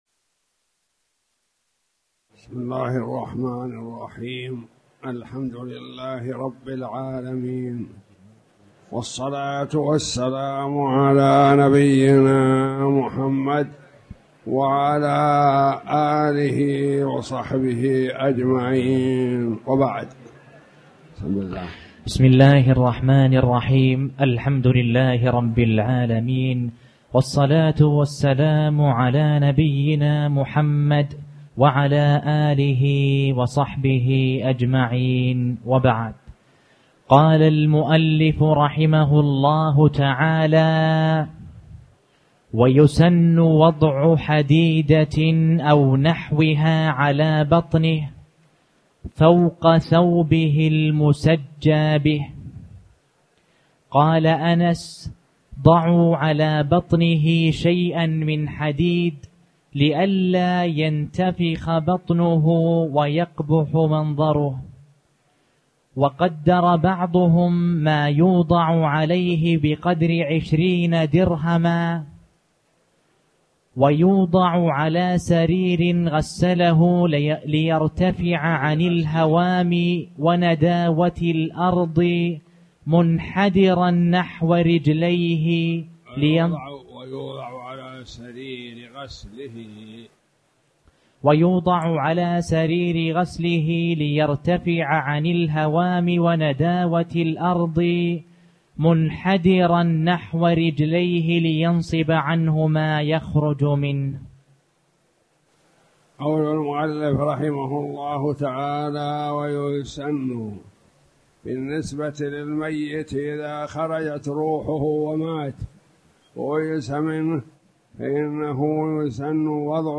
تاريخ النشر ١٣ ذو القعدة ١٤٣٨ هـ المكان: المسجد الحرام الشيخ